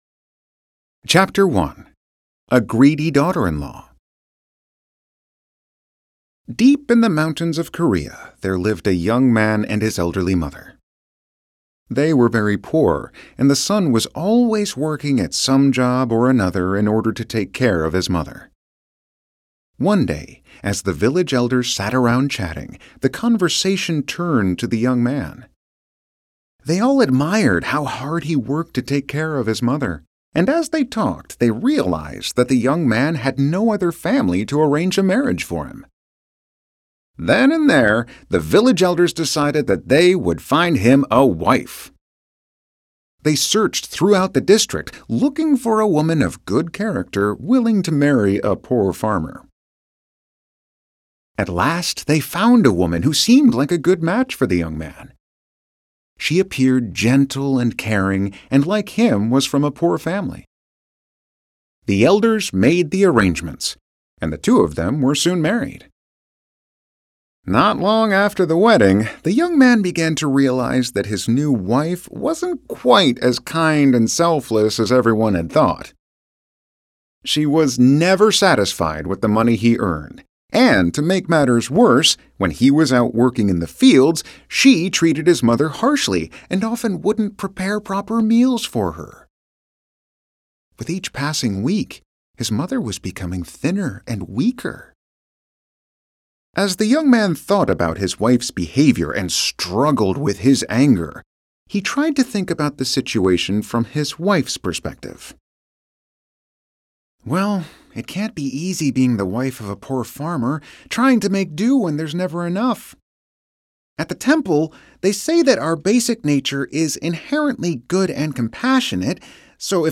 A wonderful audio edition the best selling book!